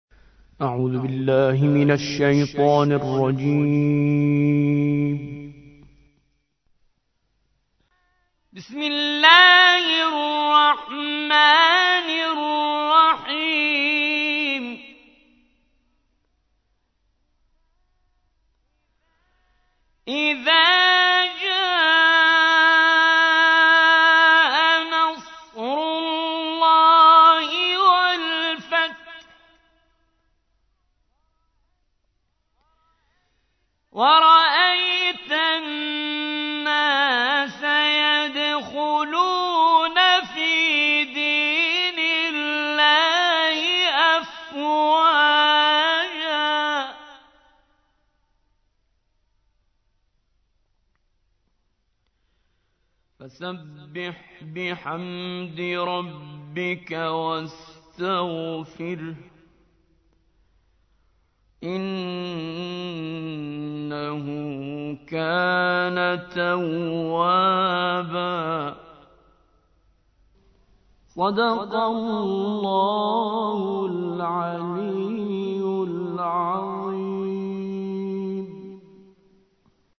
تحميل : 110. سورة النصر / القارئ كريم منصوري / القرآن الكريم / موقع يا حسين